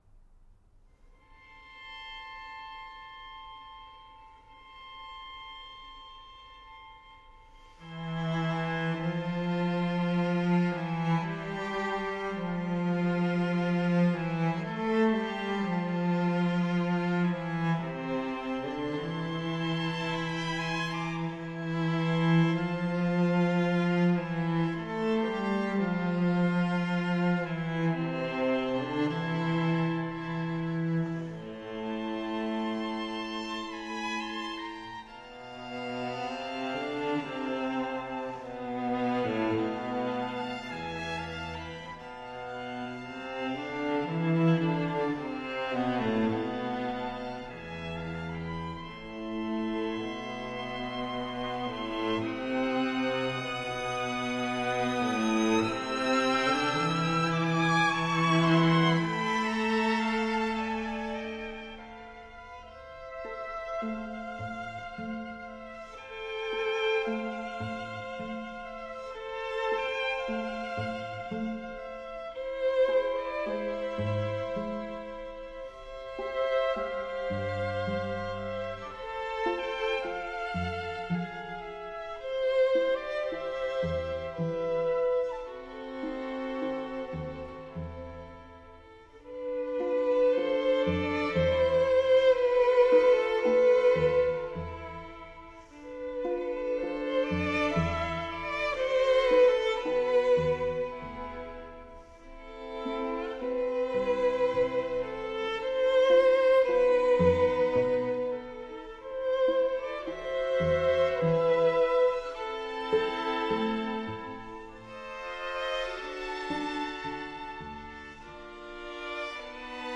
slow movement